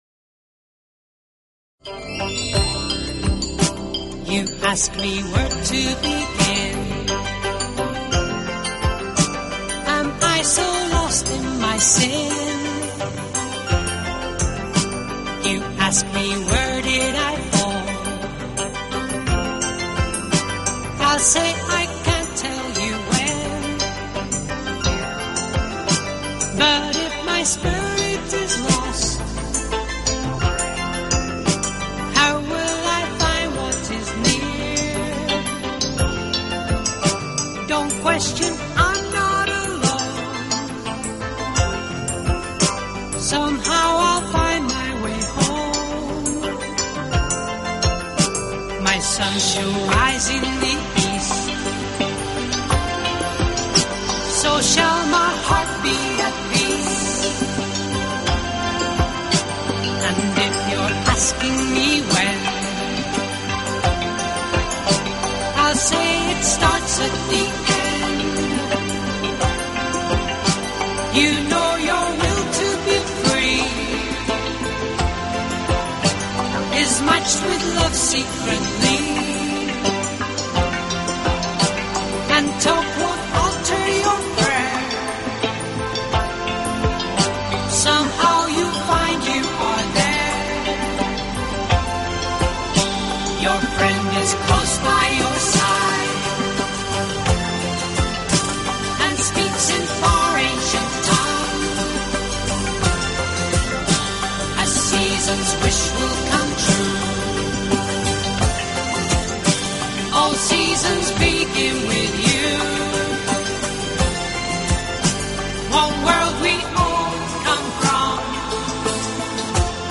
Talk Show Episode, Audio Podcast, Straight_from_the_Heart and Courtesy of BBS Radio on , show guests , about , categorized as